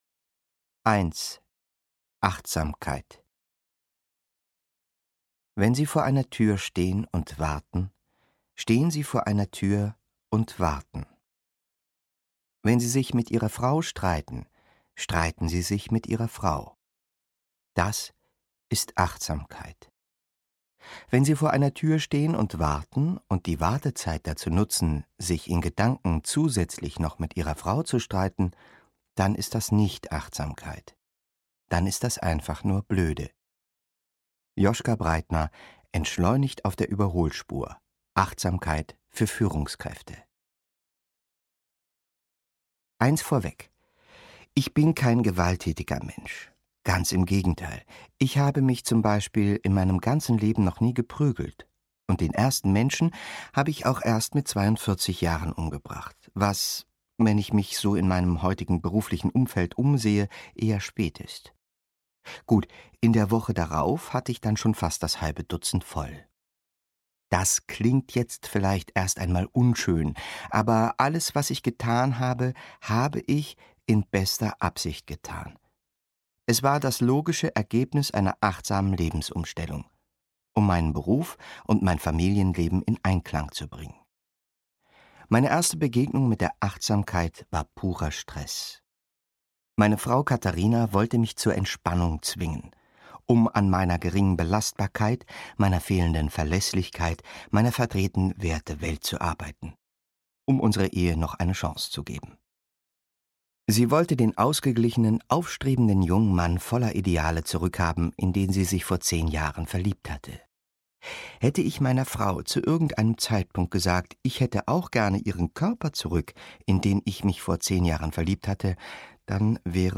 Achtsam morden (DE) audiokniha
Ukázka z knihy
• InterpretMatthias Matschke